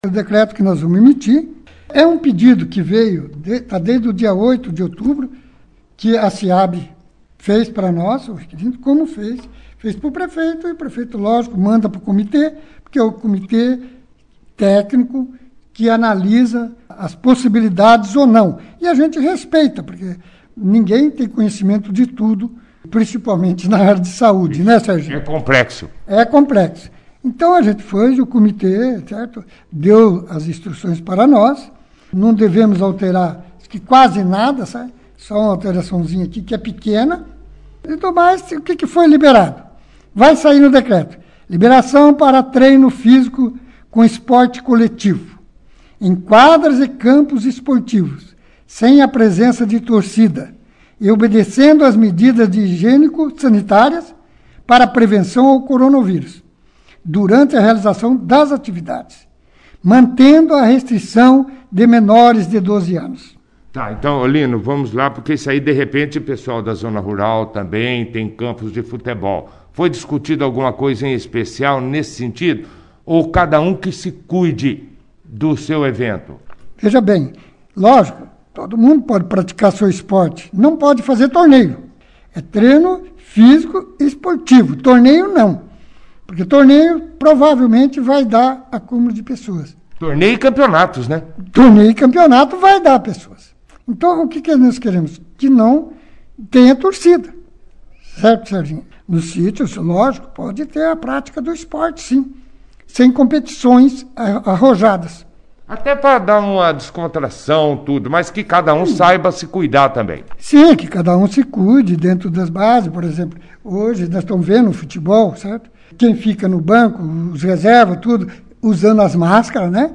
O prefeito Lino Martins, participou da 2ª edição do jornal Operação Cidade desta sexta-feira, 16/10/20, falando sobre flexibilizações definidas pelo Comitê do Covid-19 de Bandeirantes, em reunião na última quinta-feira, 15/10, e que serão inseridas no decreto que dispõe sobre a abertura do comércio e o funcionamento da indústria e prestação de serviço no município, observando medidas para o enfrentamento da epidemia de infecção humana pelo novo coronavírus, que serão publicadas neste sábado, 17, quanto passará a ter validade.